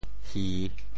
How to say the words 戏歇棚拆 in Teochew？
潮州 hi3 hiah4 bên5 tiah4 潮州 0 1 2 3